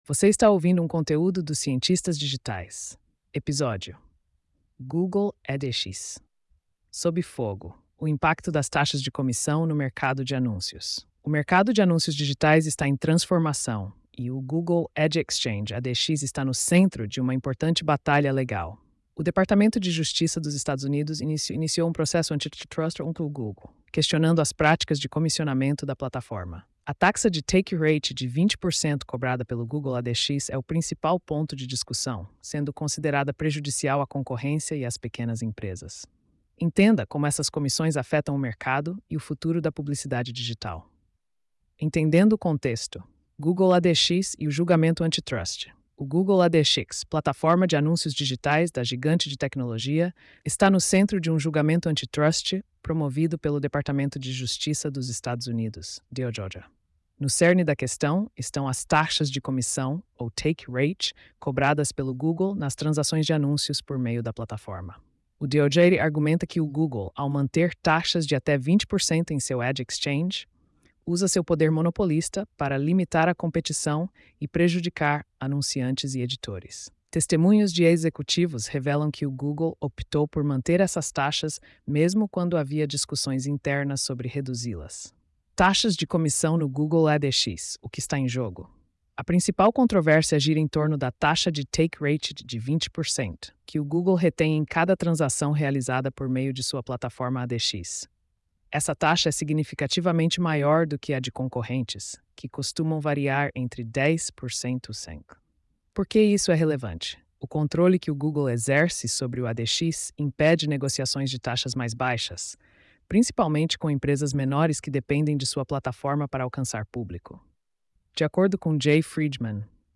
post-2519-tts.mp3